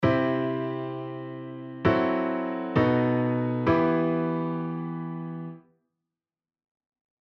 ツーファイブにした後のコード進行はC⇒F#m7-5⇒B7⇒Emになります。
さっきよりも「Emが主役になった感」が強くなり、無事にCからEmへ転調できました。